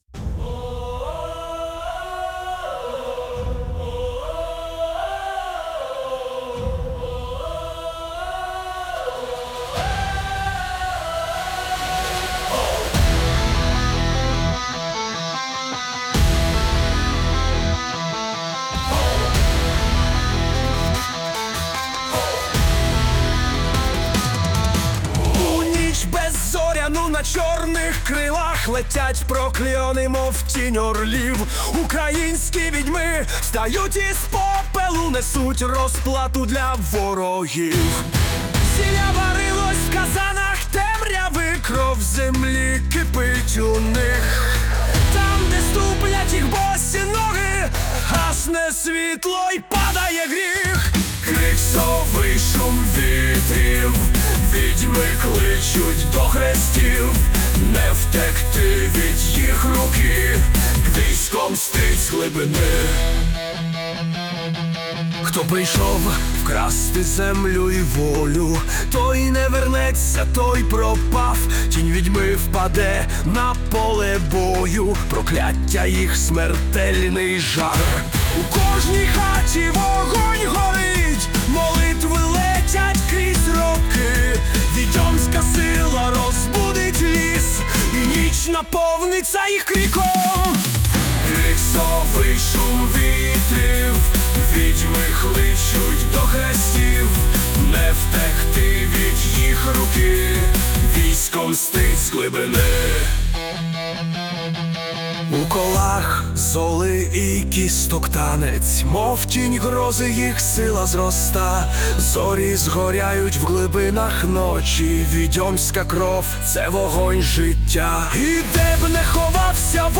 ТИП: Пісня
ВИД ТВОРУ: Авторська пісня